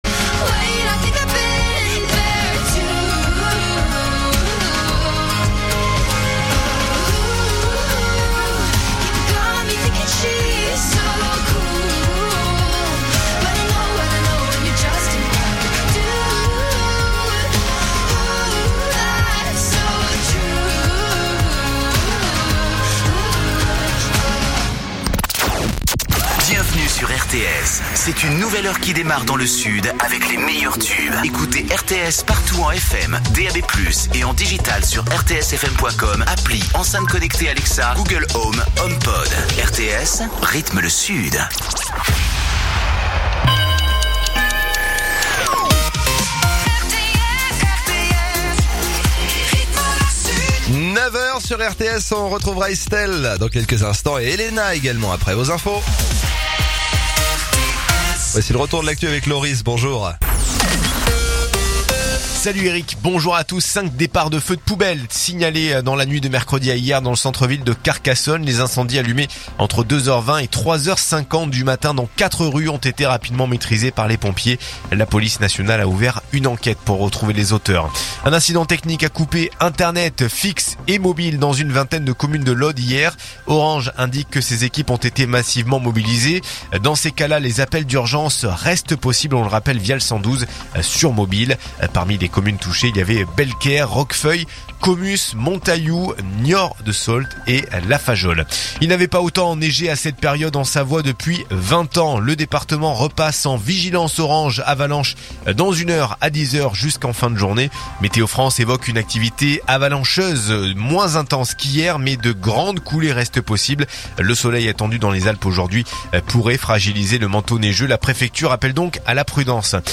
info_narbonne_toulouse_357.mp3